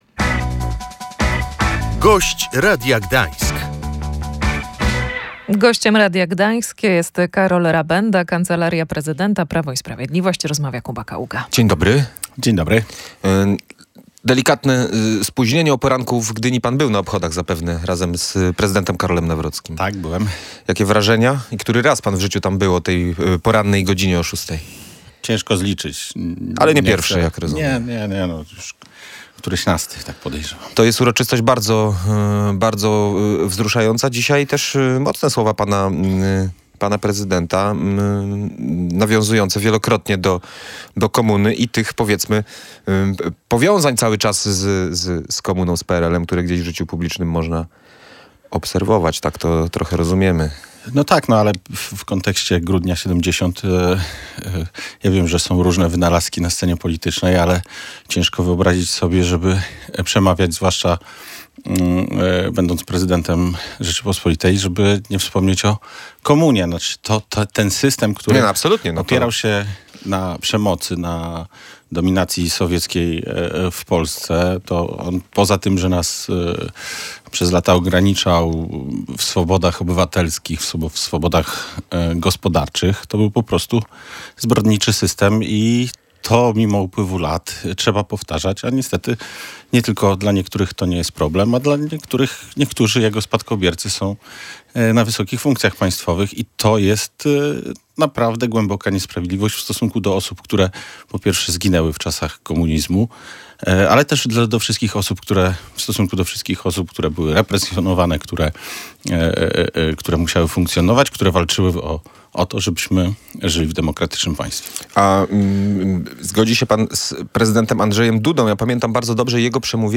Ofiary masakry grudniowej z 1970 roku czy stanu wojennego wciąż nie uzyskały odpowiedniego zadośćuczynienia – mówił w Radiu Gdańsk Karol Rabenda z Kancelarii Prezydenta Rzeczypospolitej Polskiej.